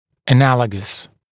[ə’næləgəs][э’нэлэгэс]аналогичный, схожий, подобный